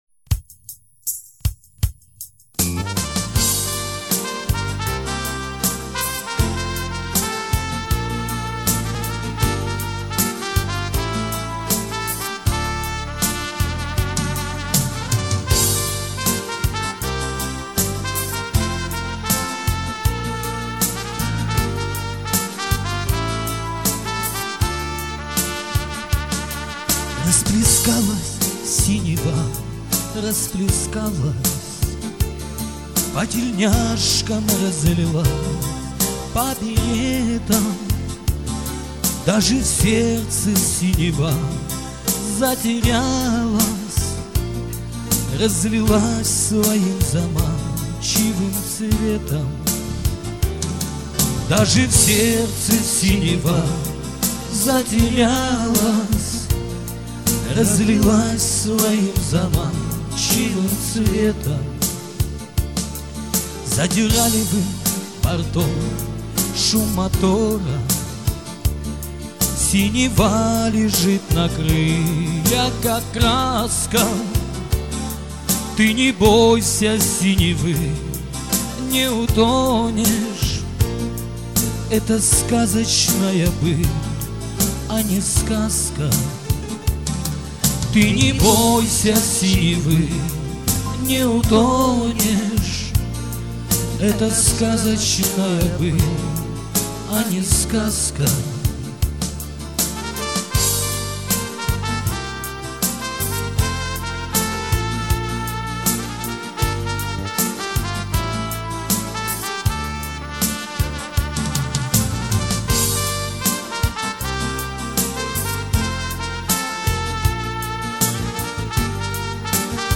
Уличные музыканты - "Афганцы" - Дембельский альбом (Название условное)